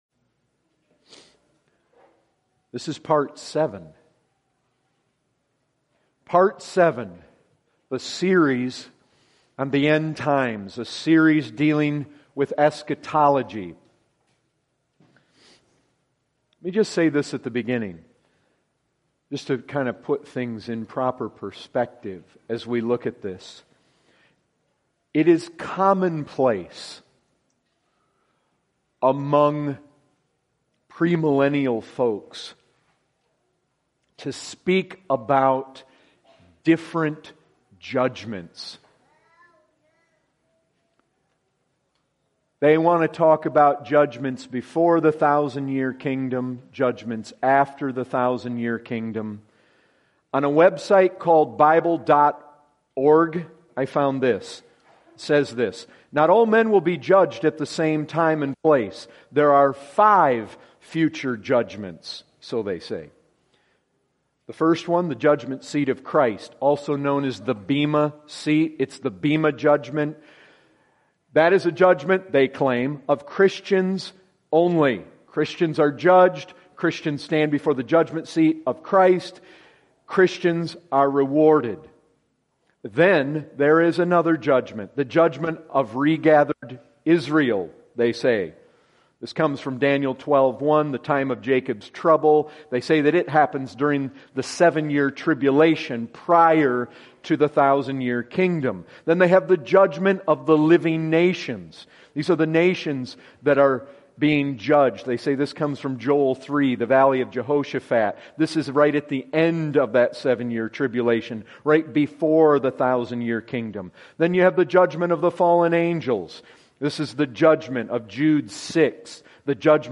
2015 Category: Full Sermons Topic